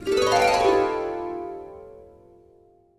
Magical Harp (3).wav